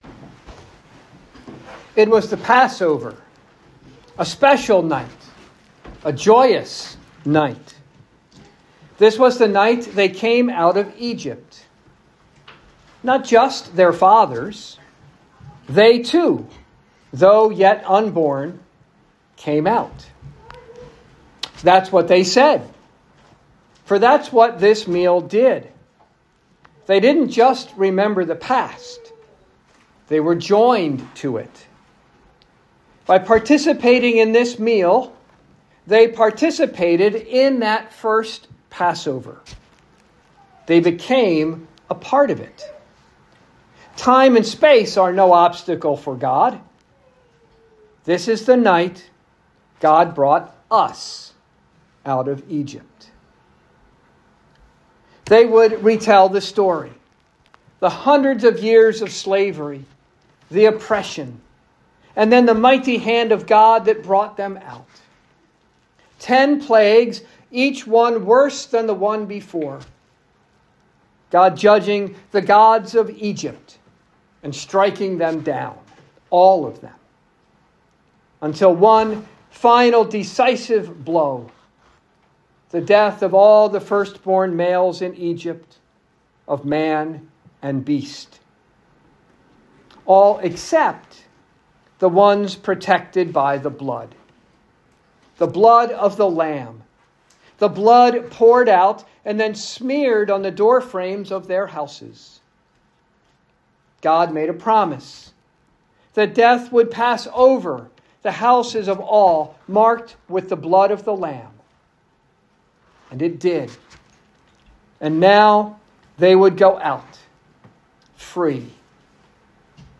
Saint Athanasius Lutheran Church Christmas Sermons 2022-